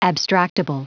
Prononciation du mot abstractable en anglais (fichier audio)
Prononciation du mot : abstractable